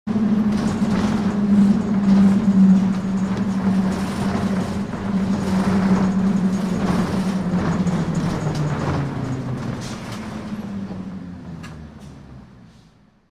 bus-stop.ogg